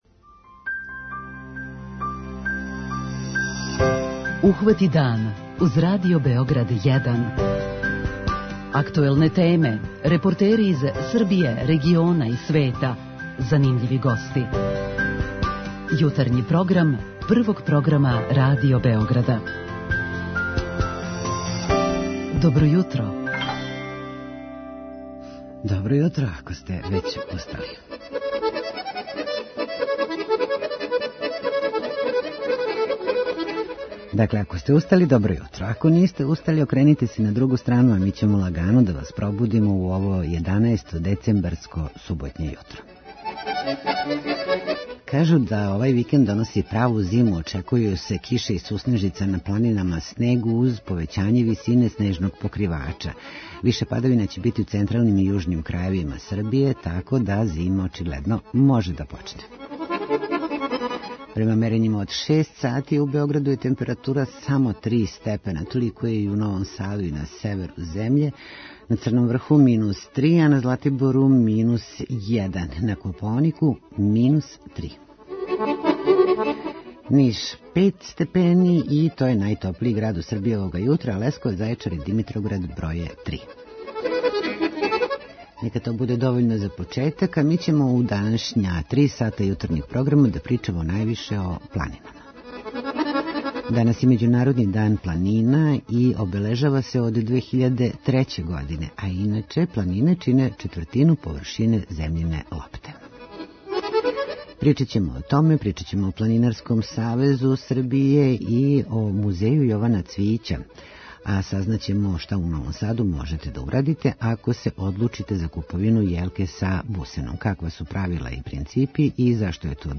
Наши дописници се јављају из Прокупља, Зрењанина Бора и Прибојске Бање.